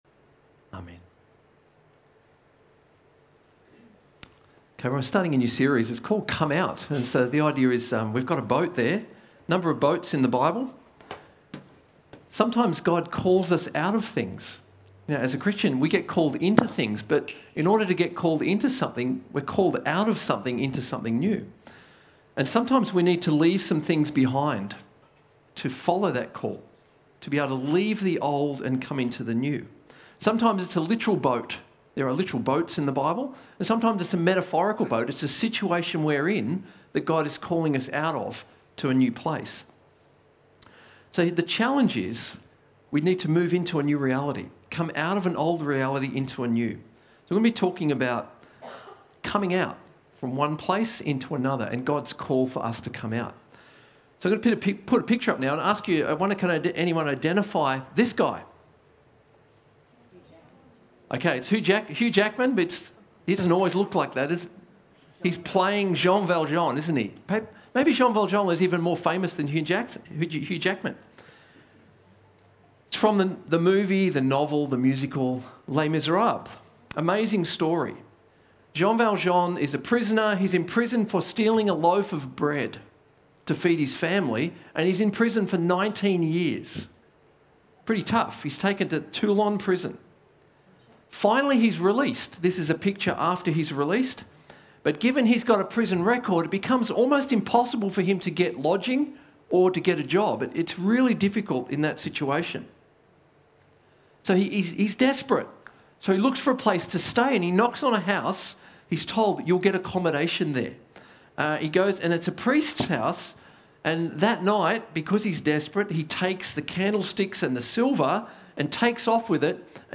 A message from the series "Called Out."